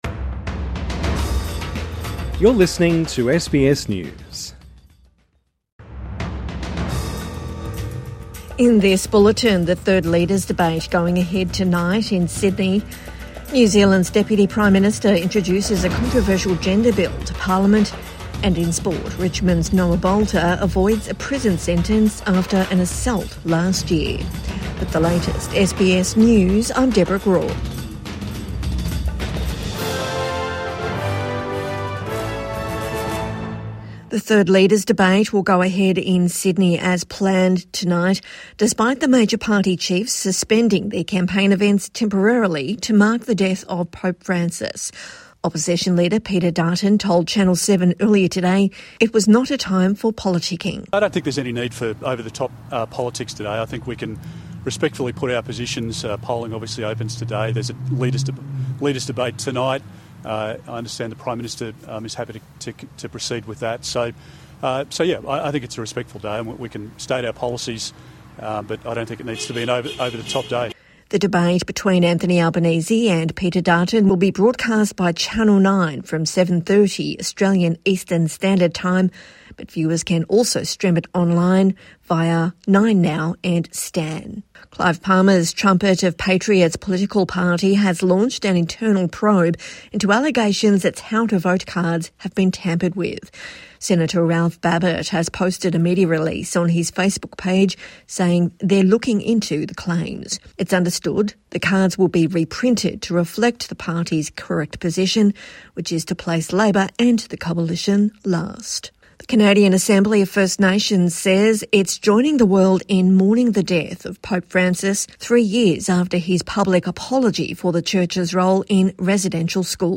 Evening News Bulletin 22 April 2025